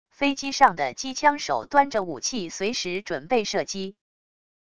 飞机上的机枪手端着武器随时准备射击wav音频